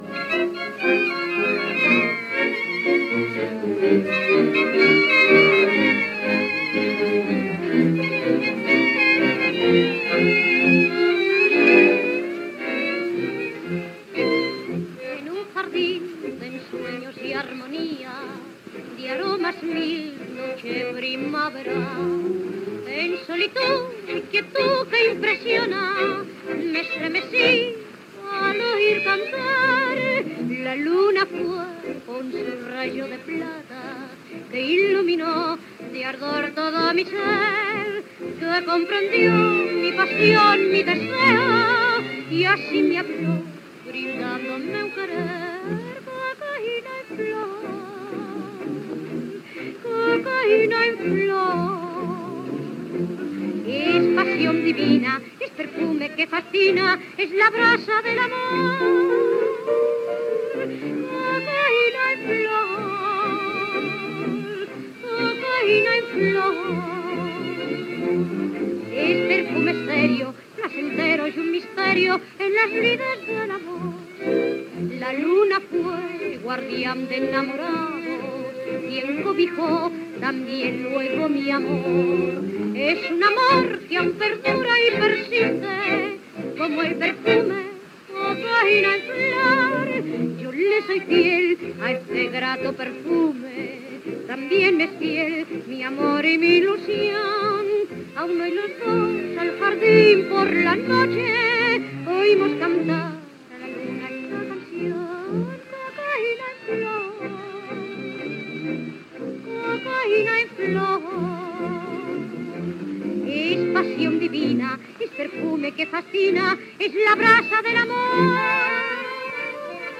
a ritme de tango